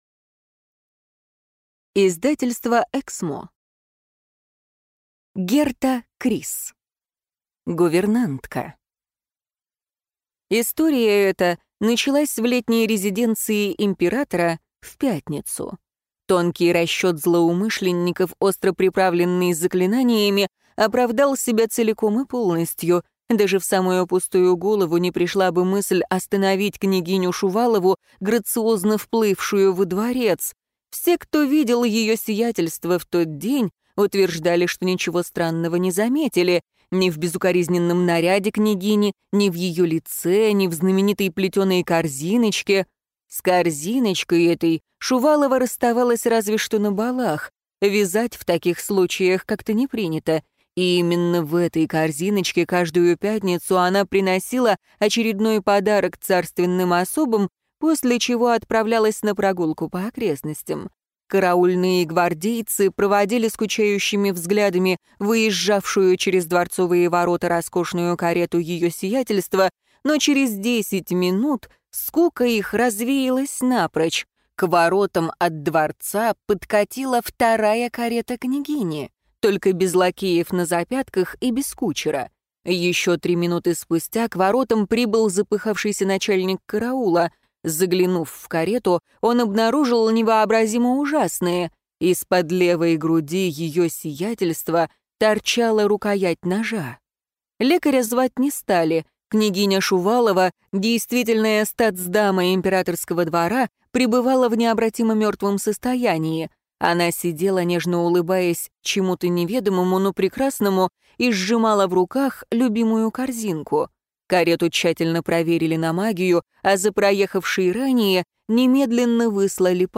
Аудиокнига Гувернантка | Библиотека аудиокниг